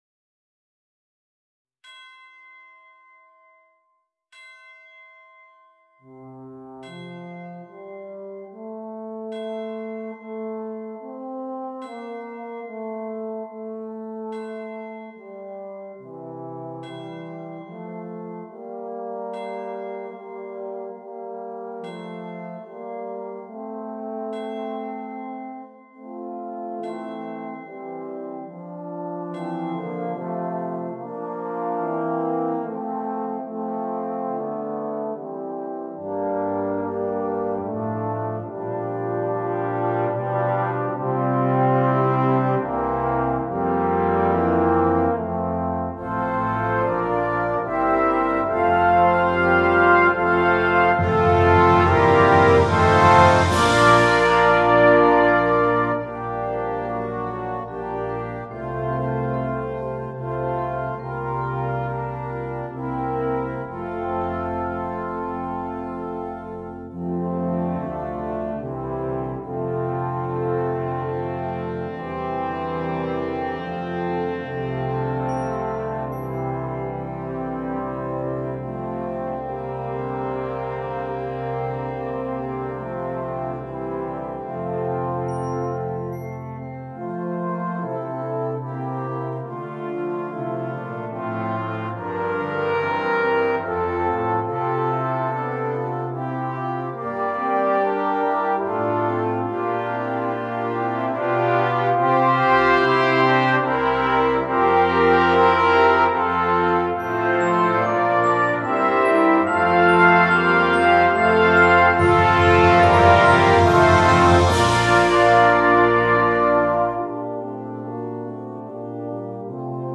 Besetzung: Brass Band (only)